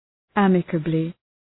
Προφορά
{‘æməkəblı}